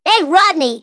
synthetic-wakewords
ovos-tts-plugin-deepponies_Bart Simpson_en.wav